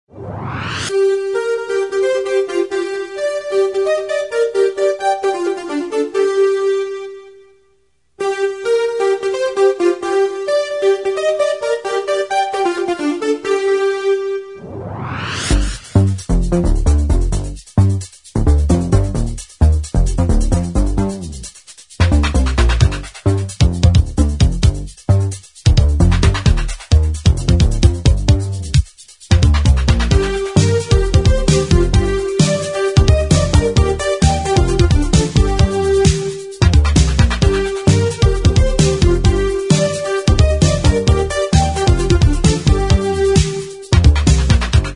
Tono para tu móvil